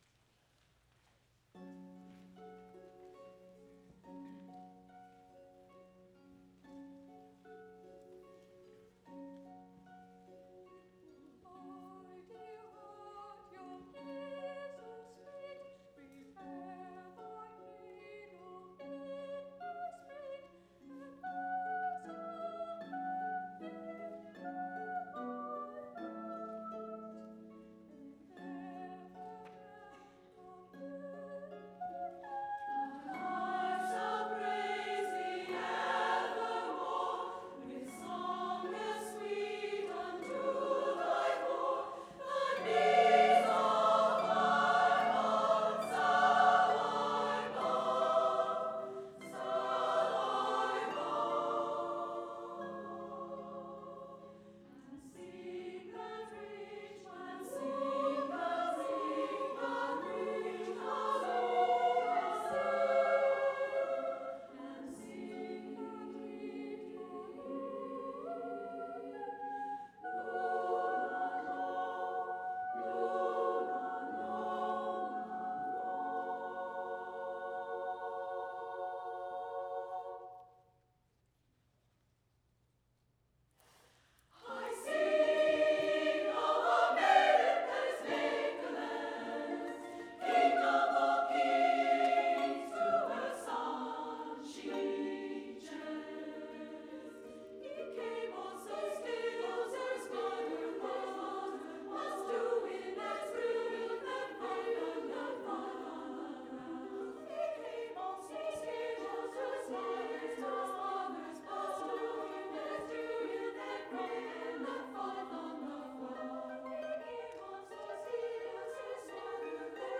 more-or-less randomly, except that I thought that the harp
The recording is unaltered from the original, except for being trimmed.
level setting. 24-bit, 44.1KHz, using an AT825 stereo cardioid mic,
20' from the center of the chorus (four rows of women), and about 8' up
The harp was about 30 degrees to the side.
concert, but you can hear them coughing, as well as the heating system
hiss and that's a sign [for me anyway] that HD-P2 is very capable.